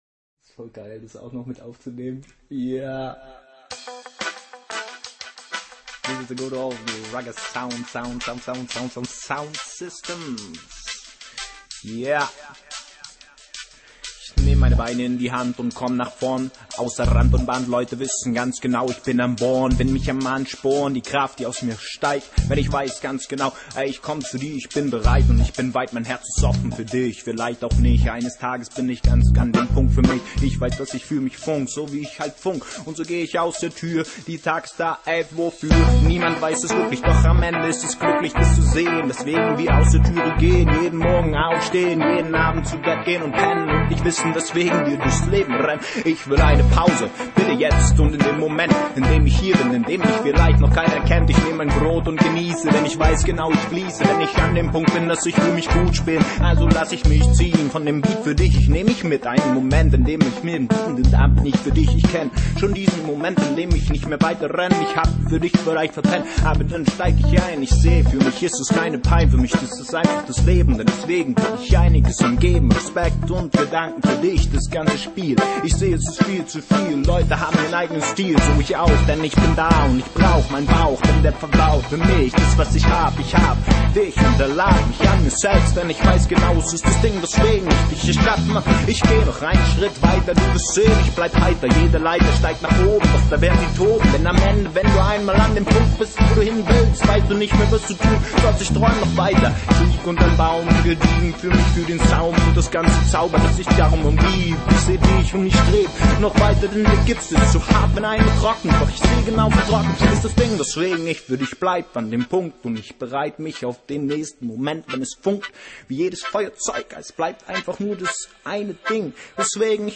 world
dub, reggae, hip hop and world music from the heart